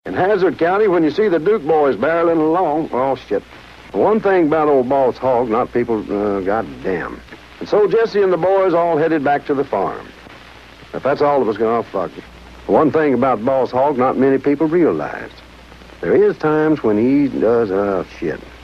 Tags: Celebrities Bloopers Barry White Celebrity bloopers Blooper Audio clips